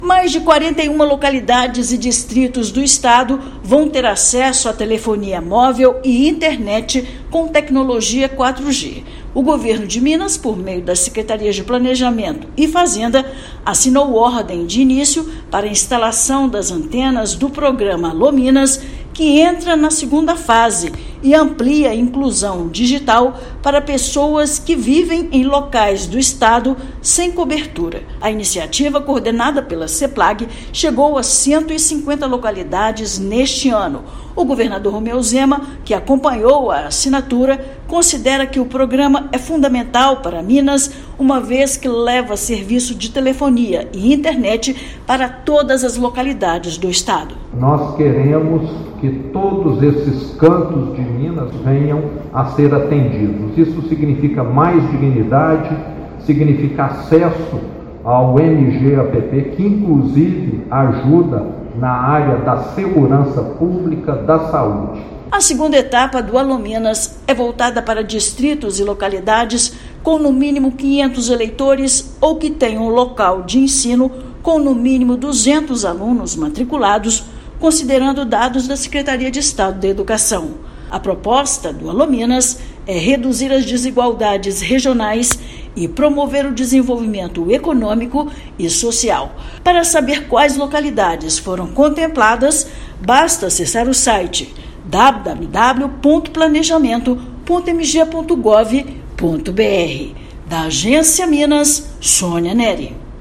[RÁDIO] Governo de Minas inicia instalação das antenas do Alô, Minas! - Fase II em mais 41 distritos e localidades do estado
Programa, que chegou a 150 distritos em 2024, ampliará inclusão digital a mais locais sem cobertura. Ouça matéria de rádio.